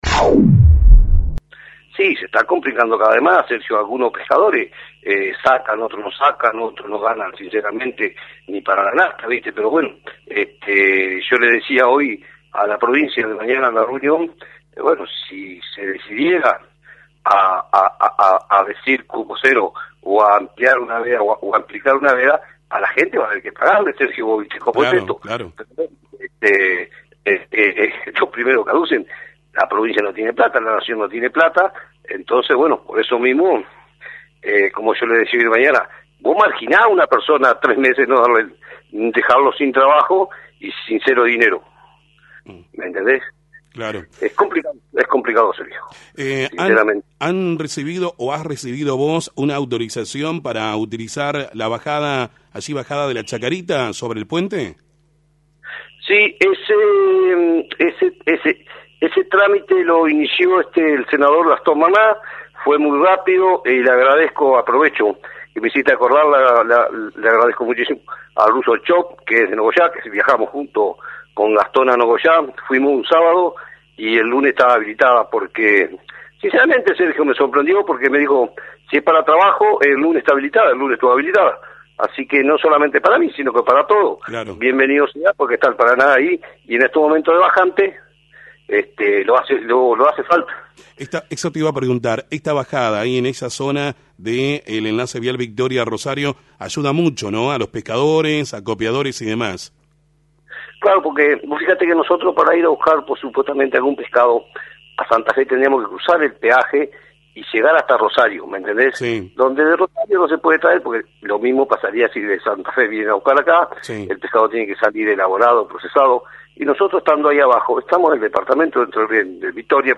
pescador